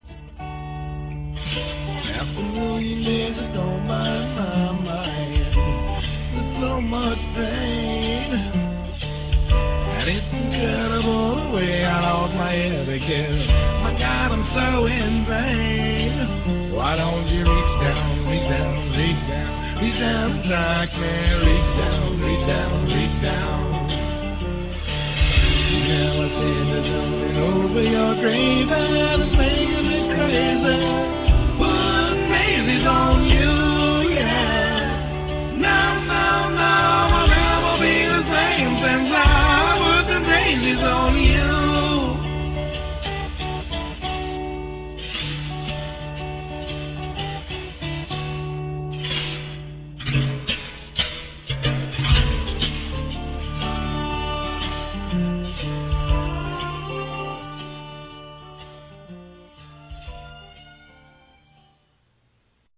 in 16 bit stereo sampled at 44 khz